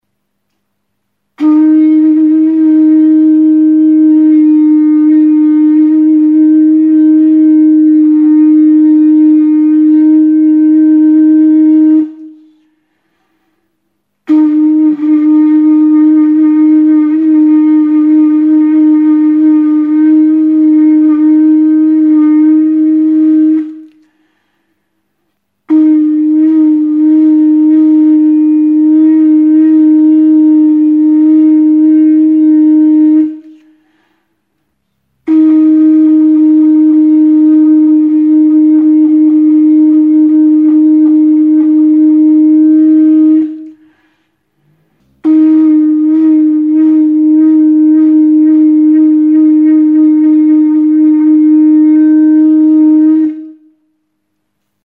Aerophones -> Lip vibration (trumpet) -> Natural (with / without holes)
Recorded with this music instrument.
Idi adar arrunta da. Punta moztua du eta bertan tronpeta gisako ahokoa.